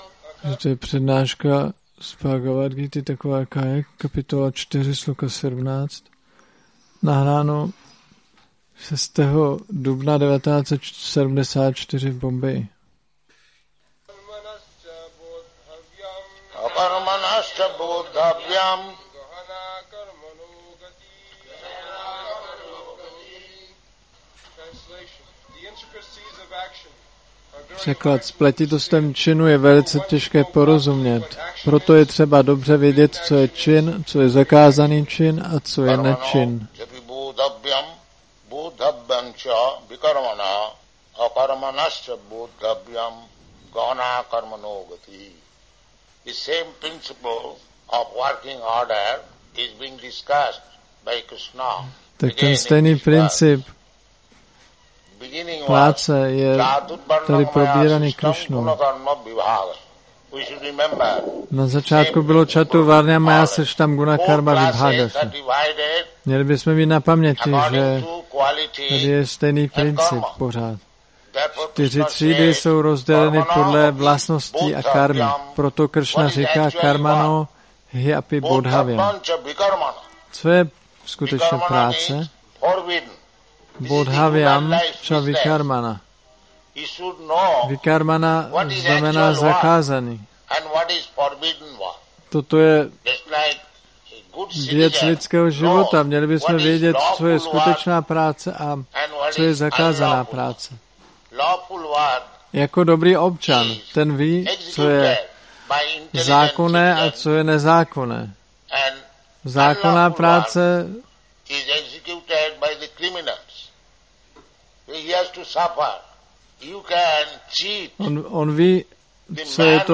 1974-04-06-ACPP Šríla Prabhupáda – Přednáška BG-4.17 Bombay